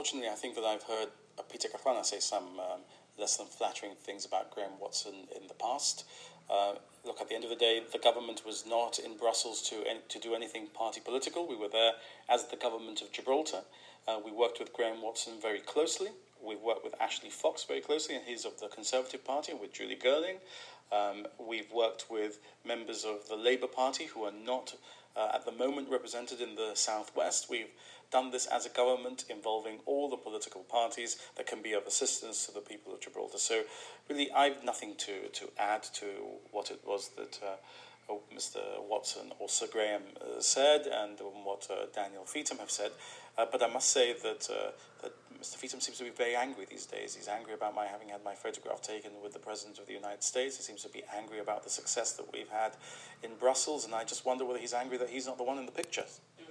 The Chief Minister was answering GBC questions following comments by Sir Graham Watson to the effect that Peter Caruana never placed much importance on Brussels, and subsequent remarks by the Leader of the Opposition, Danny Feetham that the Lib Dem MEP should stay out of Gibraltar's party politics.